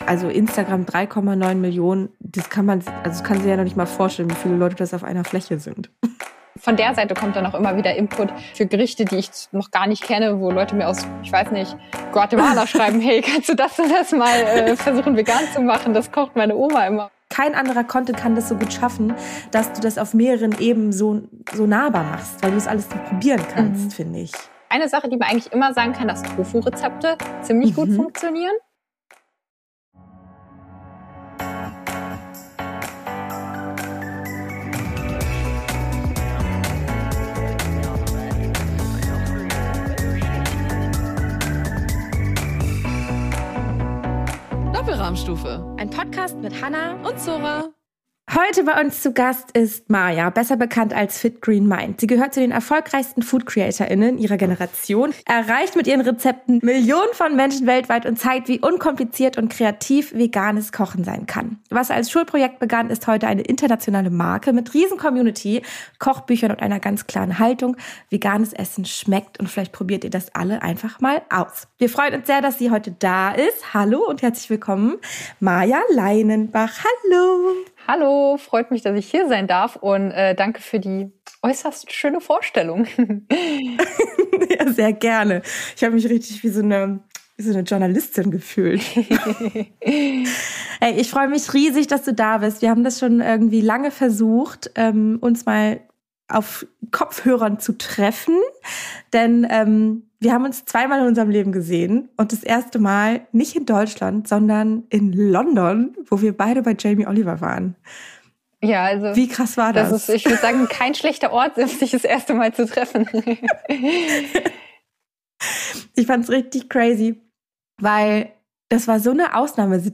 Und genau dort beginnt auch das Gespräch.